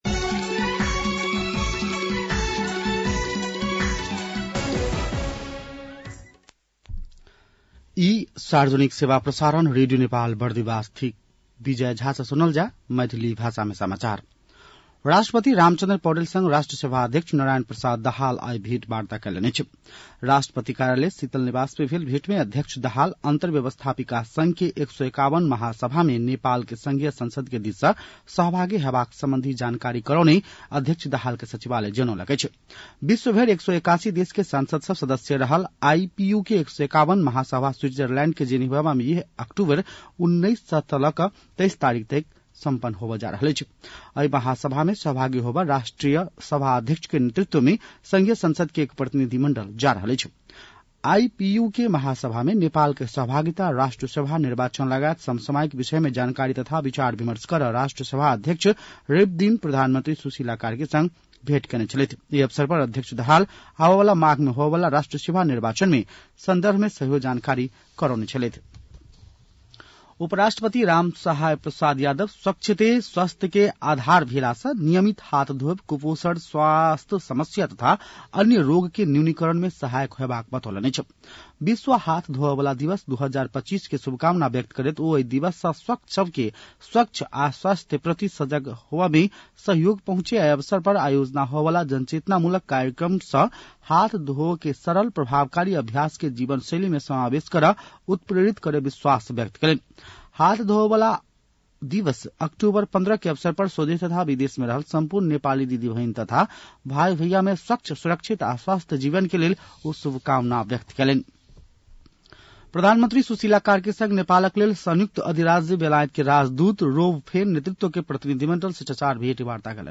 An online outlet of Nepal's national radio broadcaster
मैथिली भाषामा समाचार : २९ असोज , २०८२